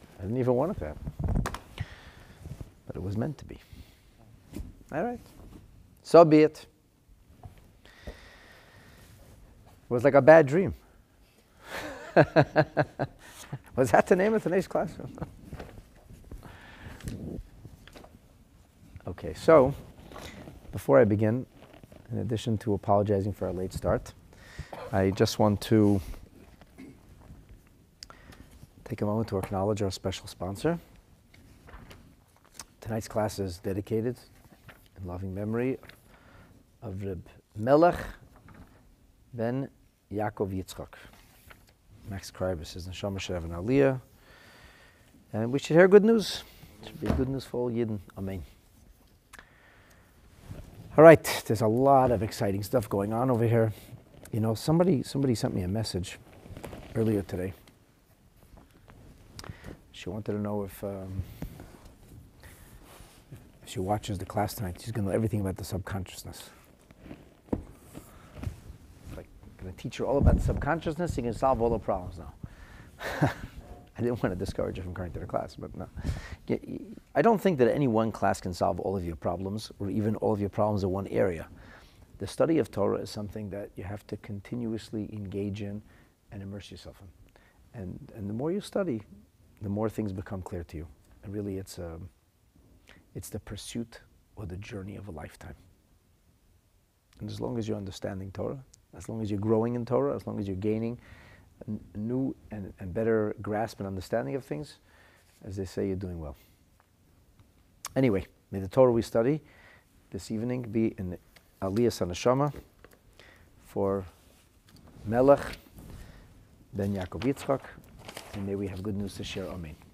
The lesson is entitled "BAD DREAMS."